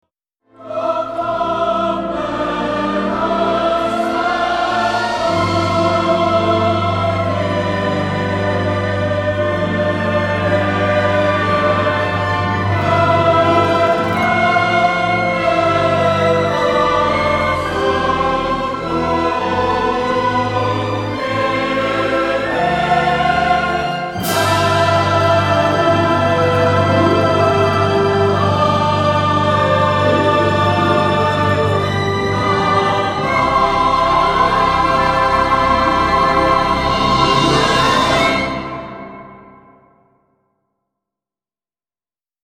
a choral fugue